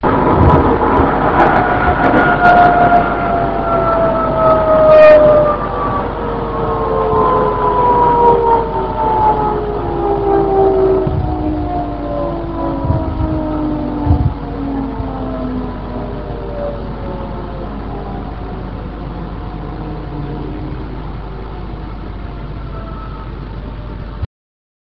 ０５系ワイドドア車加速音 東海神→飯山満 82.7Kb RealAudio形式
地下駅からの出発です。後半爆音になってしまいました。